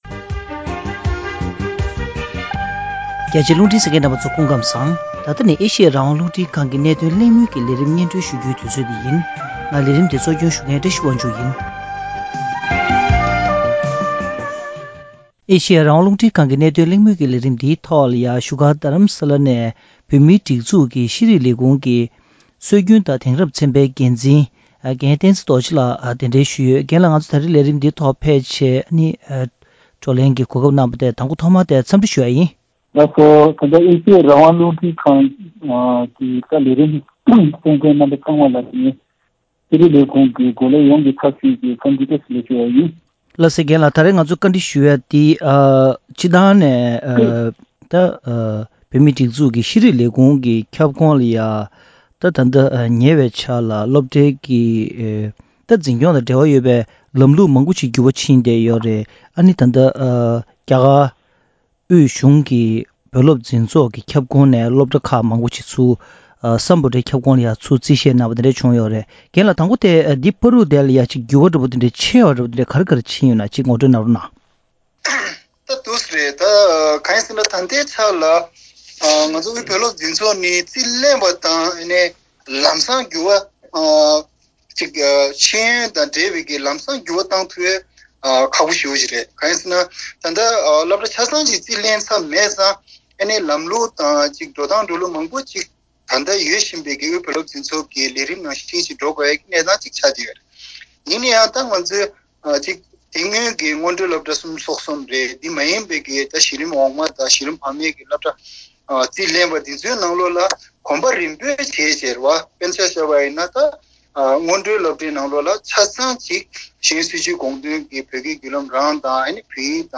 བོད་མིའི་ཤེས་ཡོན་སྲིས་བྱུས་དང་དེས་སློབ་མ་ཐར་སོན་ཡོང་བཞིན་པའི་སློབ་ཕྲུག་གི་ཐོག་ལ་ཕན་ནུས་ཇི་བྱུང་སོགས་ཀྱི་སྐོར་གླེང་མོལ།